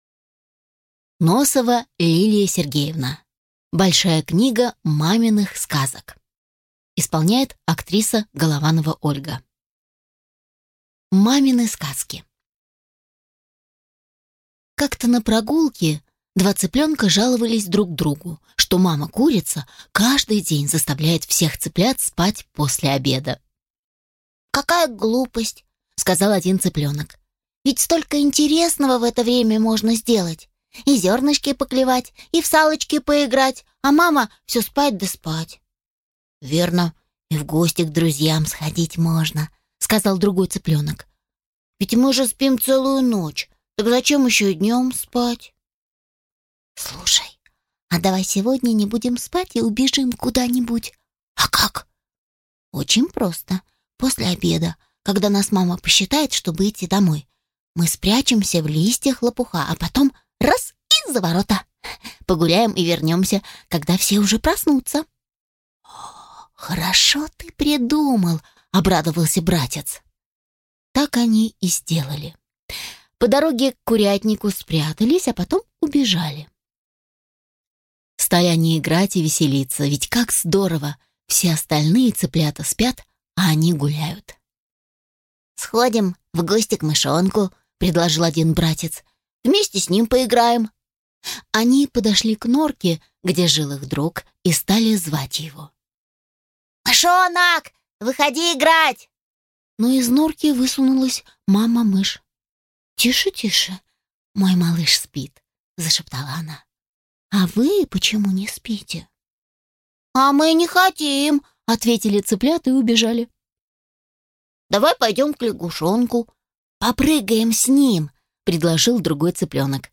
Аудиокнига Большая книга маминых сказок | Библиотека аудиокниг